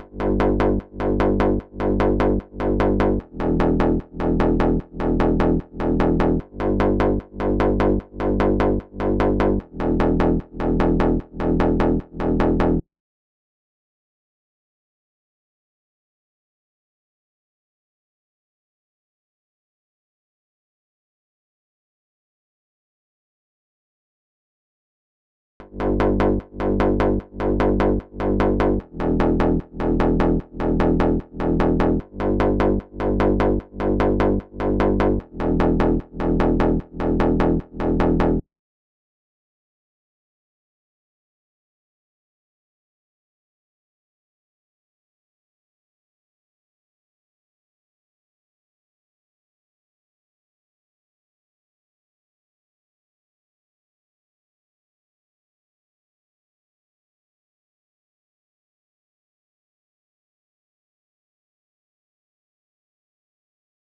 🔹 52 Premium Serum Presets built for synthwave, retro pop, and nostalgic melodic house.
Analog-Inspired Basses – Fat, gritty, and rich with retro character